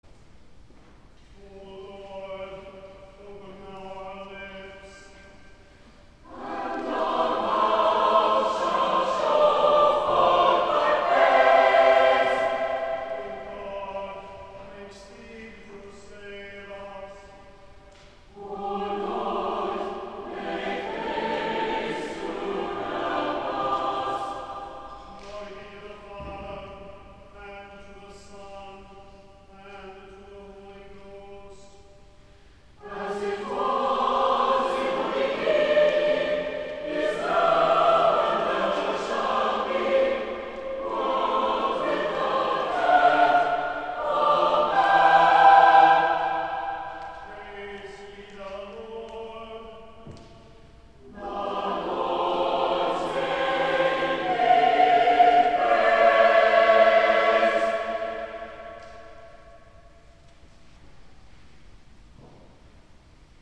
Festal Choral Evensong and Address for St. Andrew's Day
Cathedral Choir
Preces - Martin Neary